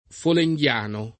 vai all'elenco alfabetico delle voci ingrandisci il carattere 100% rimpicciolisci il carattere stampa invia tramite posta elettronica codividi su Facebook folenghiano [ fole jgL# no ] agg. — del poeta Teofilo Folengo (1491-1544)